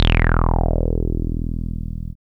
77.08 BASS.wav